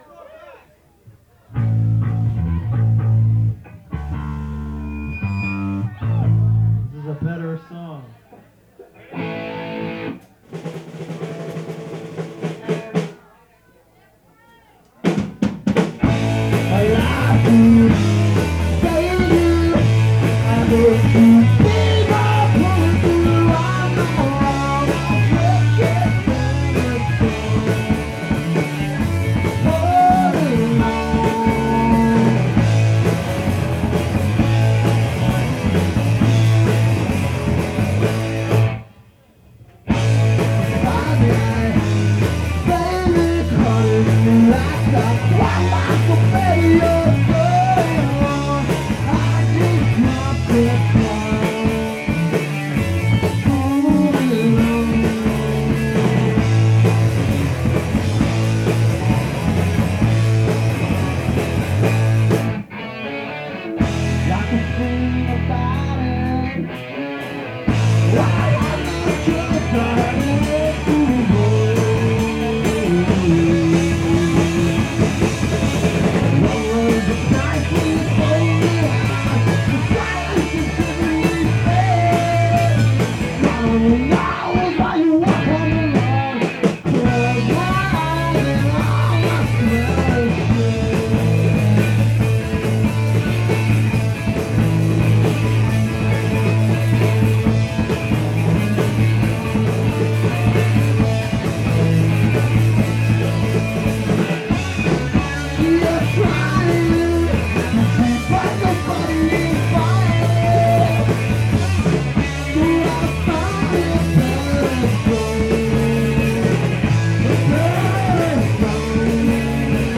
Live Tapes